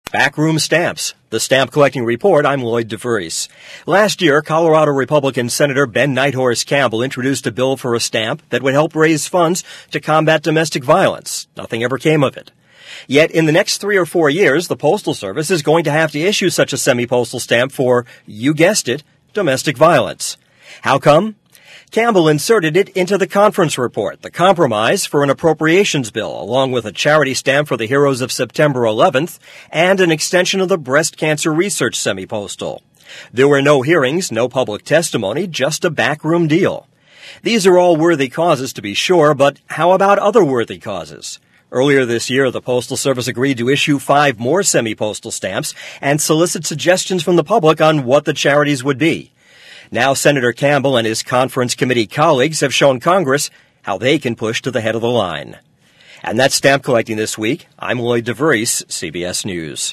For broadcast on CBS Radio Network stations